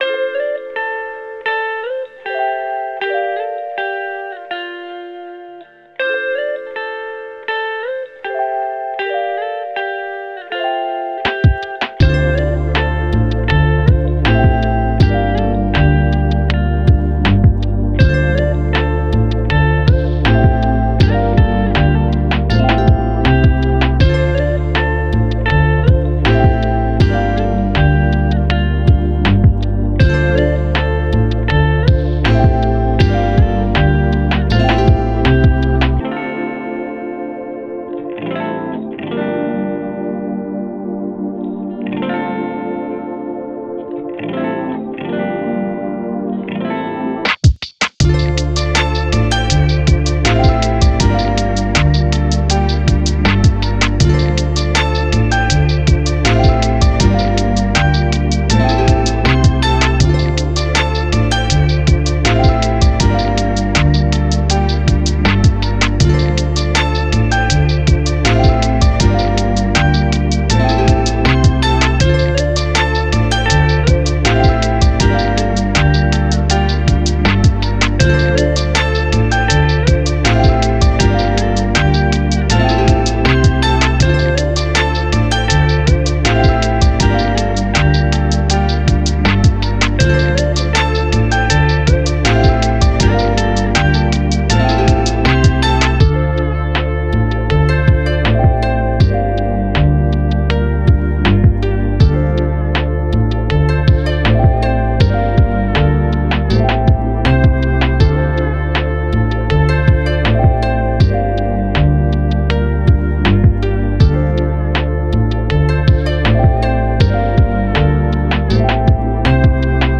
Genre: Chillout Tema: chillout, hip hop, instrumental